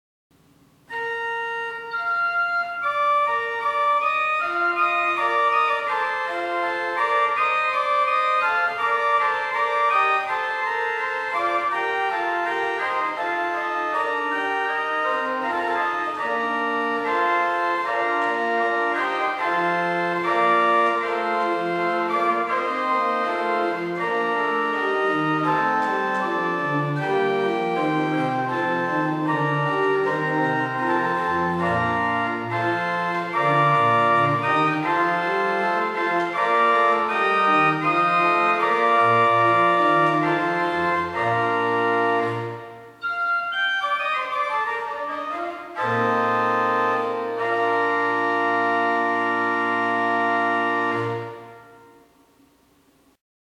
The sound, in fact, is very similar to a Tannenberg organ.
Played on the Principal stops 8' and 4'.